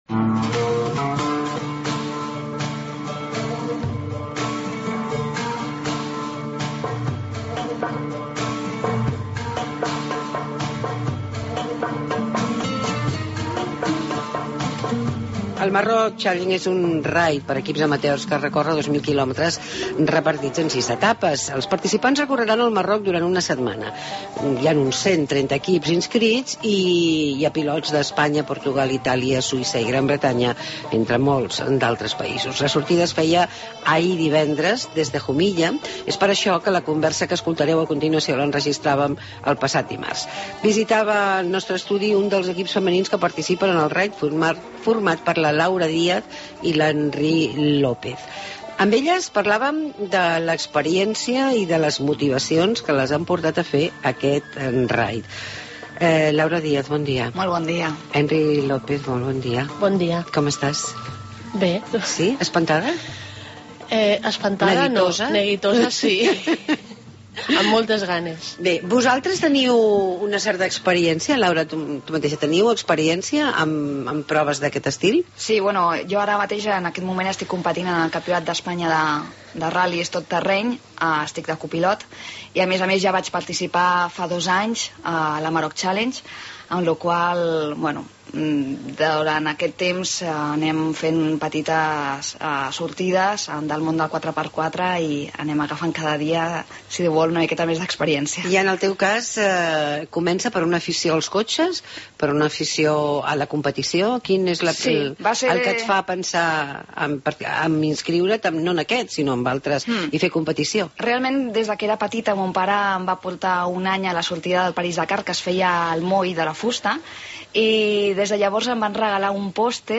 AUDIO: Ahir va començar el Maroc Challenge, un Raid low cost i solidari. Vam parlar amb dues de les seves participants